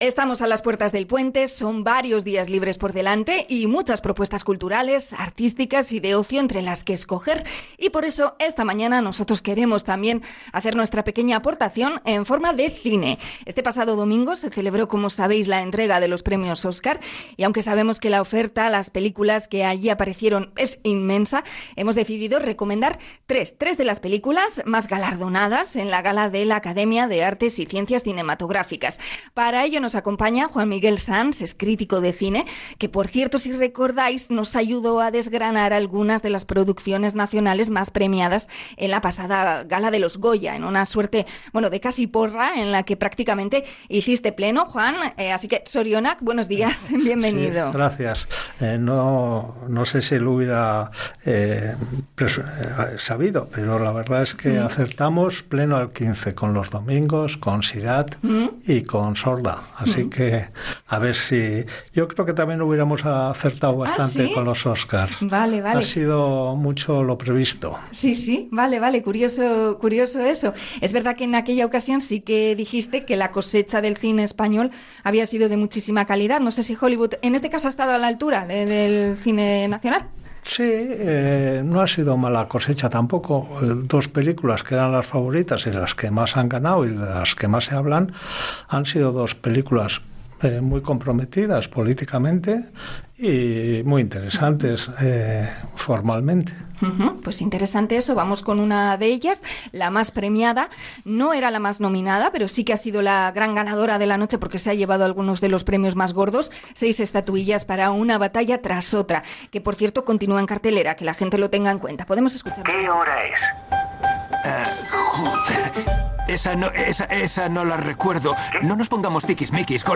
Espacio sobre los Oscars con el crítico de cine